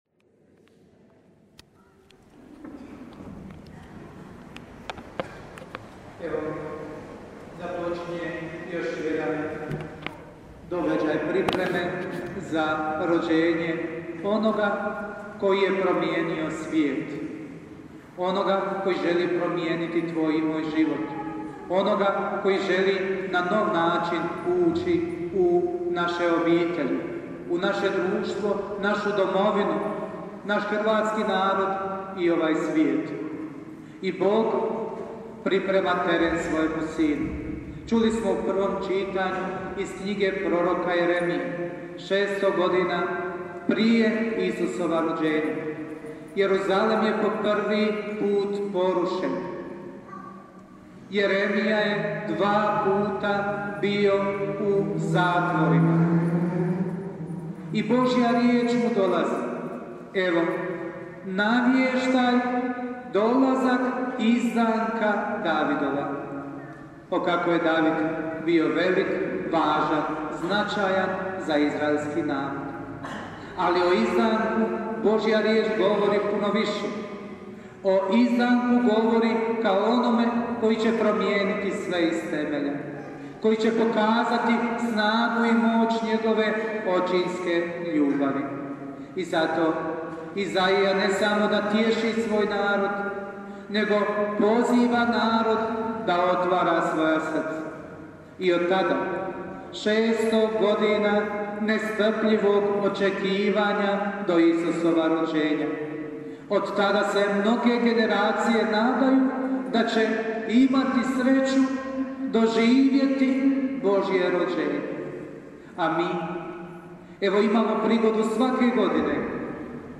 PROPOVJED: